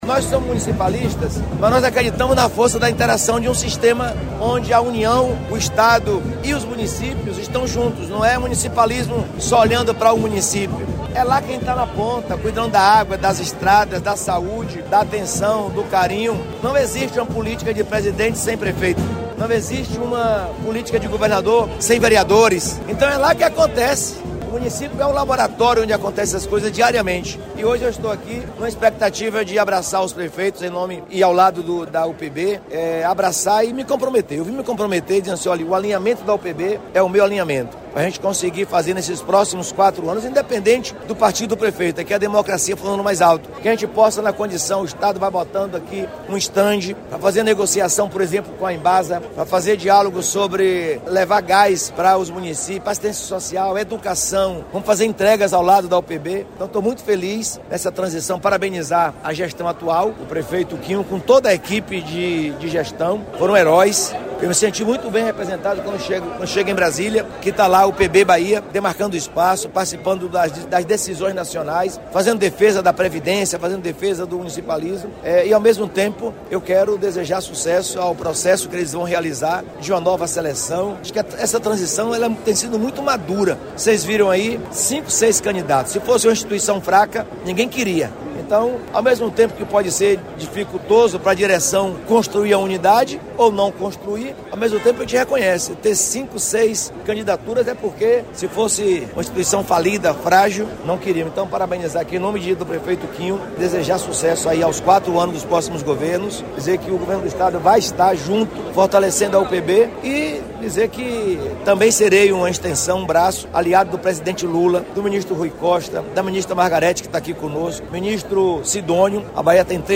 O governador Jerônimo Rodrigues esteve na abertura do evento e falou da interação entre os poderes